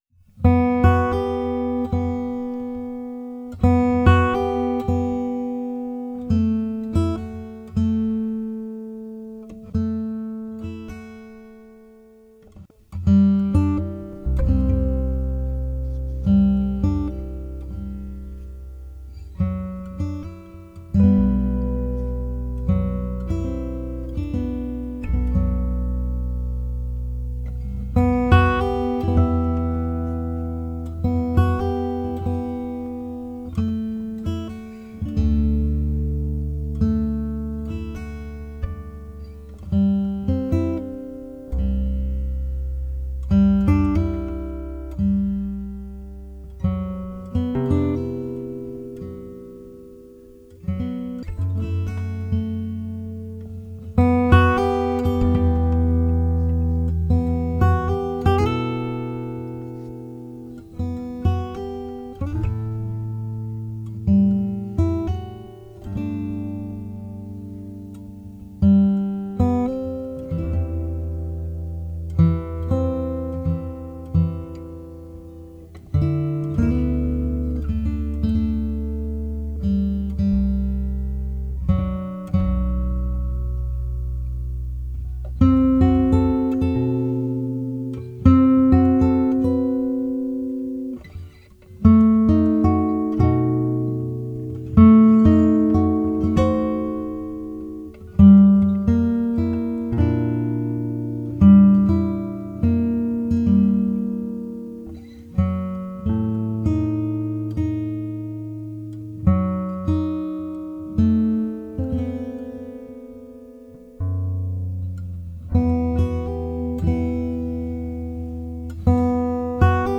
Instrumentals for Guitar